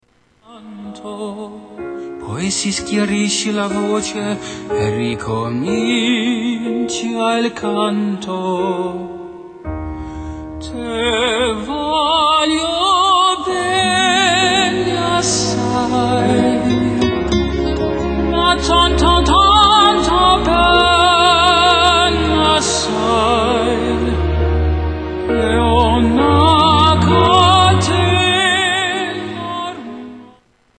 Category: Television   Right: Personal
Opera